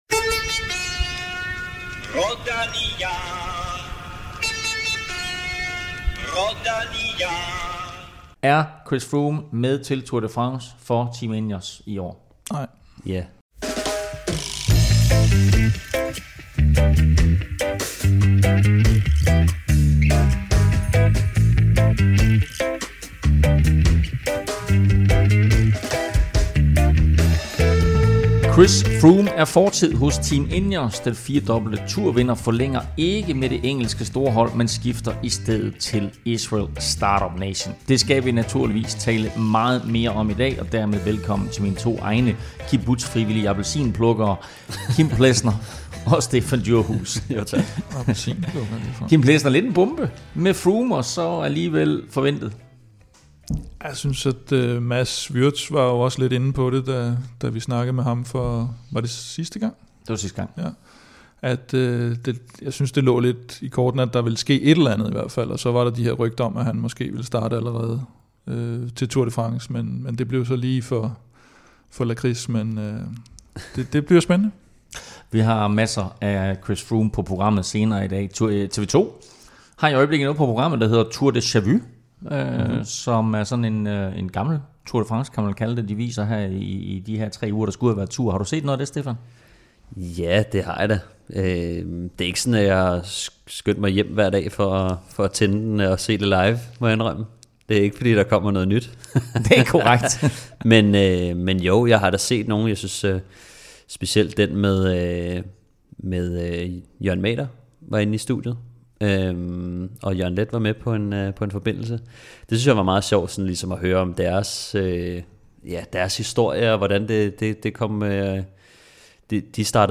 Det drøfter vi i studiet, hvor der også bliver tid til et interview med Cecilie Uttrup Ludwig, som netop har forlænget sin kontrakt med FDJ, uden at have kørt et eneste løb for dem.